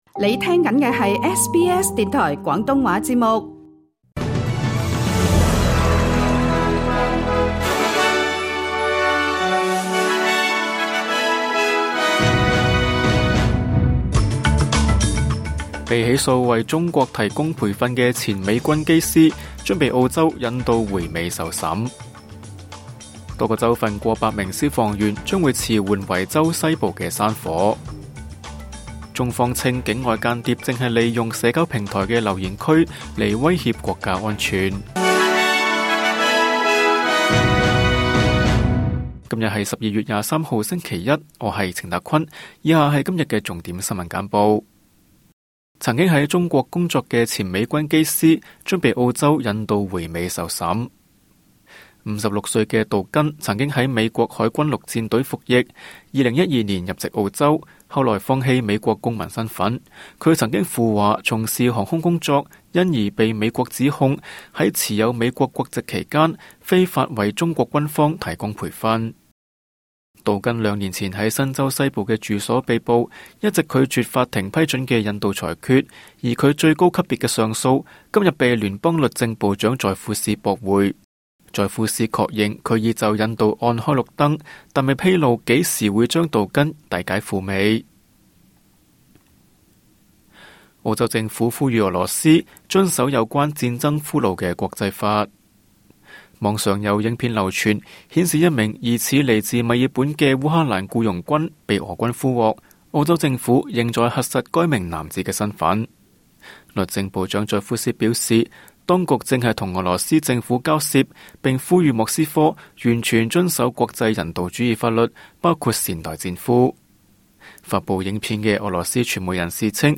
SBS 廣東話晚間新聞